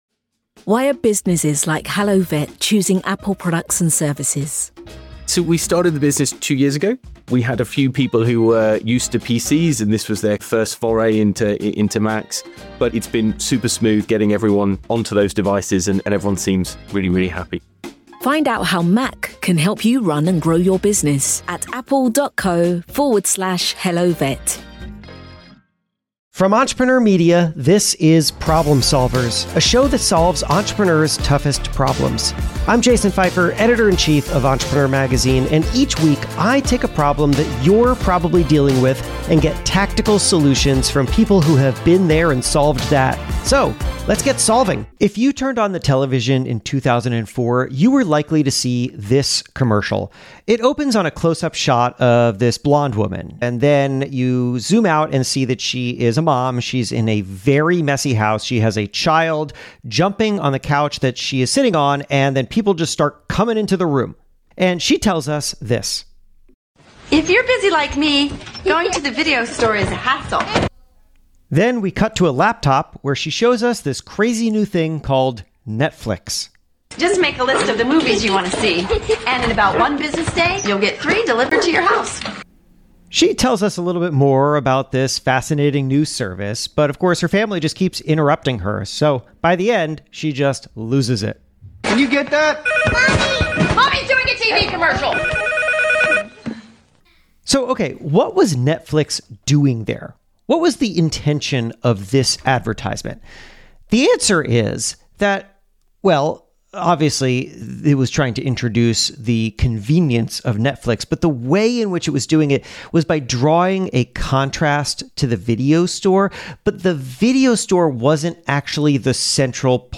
Together, they break down how companies can define what they stand against, win customers, and become the go-to name in their category. This is a packed conversation about how to create campaigns that resonate and understand the strategy behind the marketing that shapes our world.